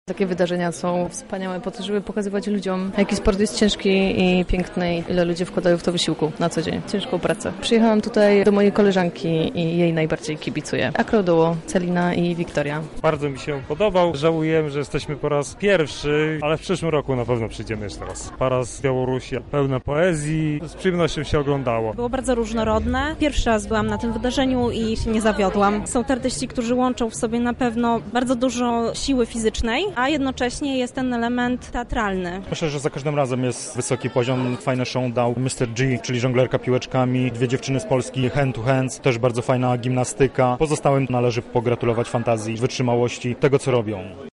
Publiczność podzieliła się z naszą reporterką swoimi odczuciami.